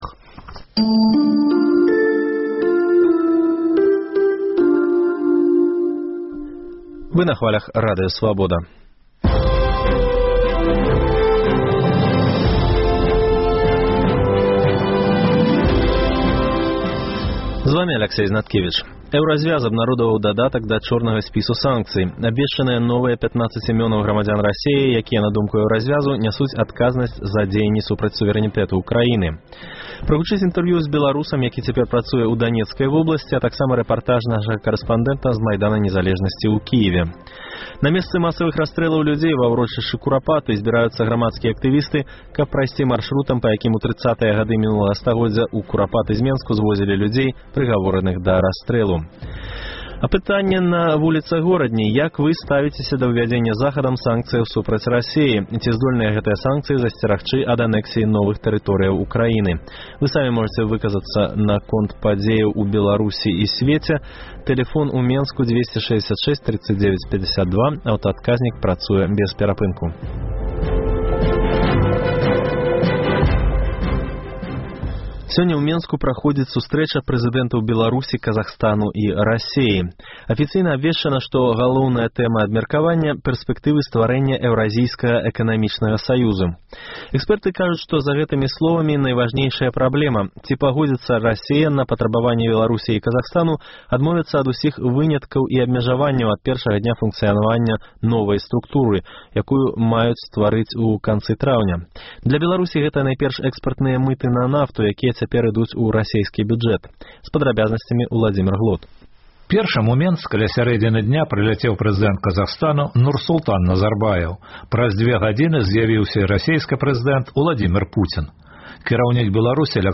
Прагучыць інтэрвію зь беларусам, які цяпер працуе ў Данецкай вобласьці, а таксама рэпартаж нашага карэспандэнта з Майдана незалежнасьці ў Кіеве.